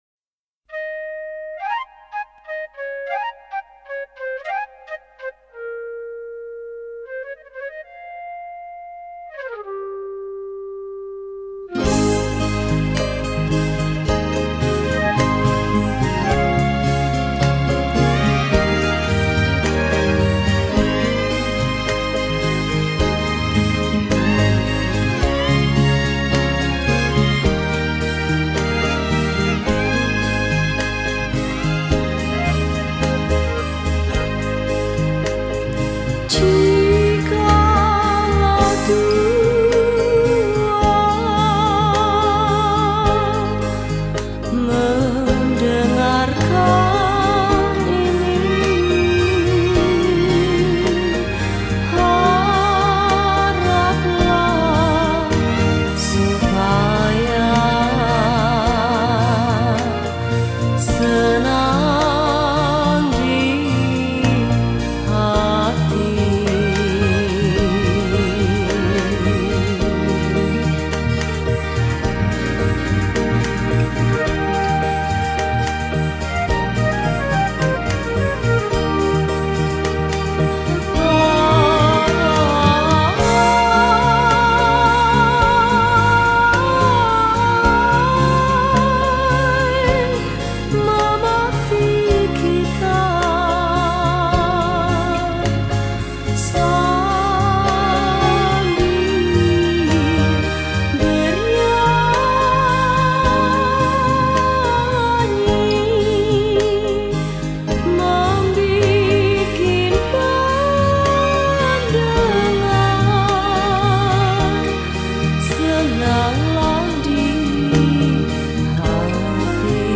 Keroncong Asli